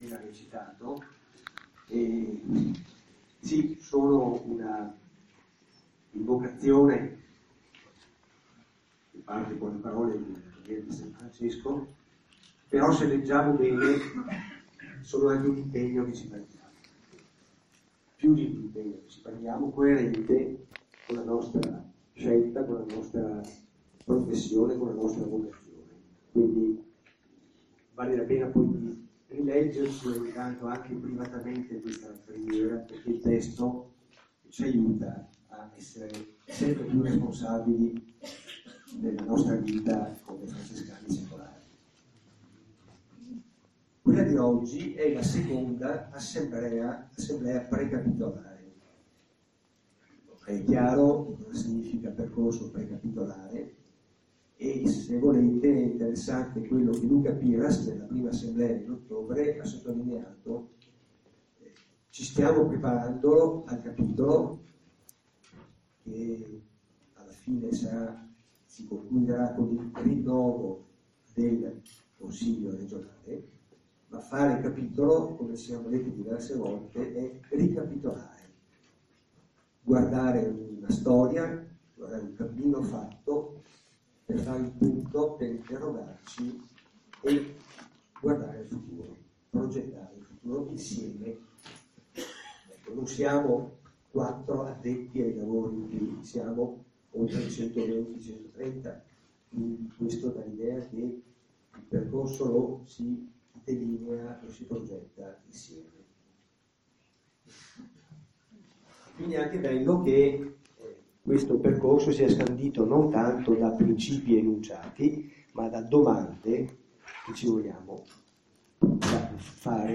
Incontro Regionale Thiene